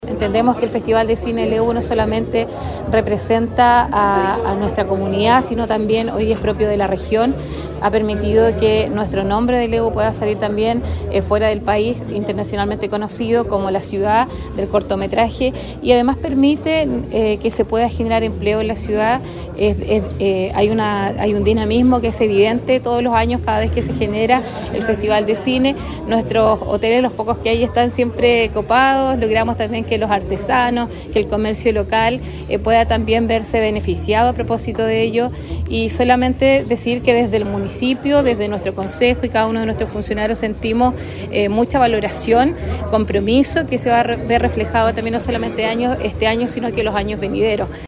En conferencia de prensa realizada en el Hotel Araucano, en el centro penquista, se dieron a conocer los principales hitos de esta edición especial, marcada por el fortalecimiento de la industria audiovisual regional y nacional, con el apoyo tanto del sector público como de la empresa privada.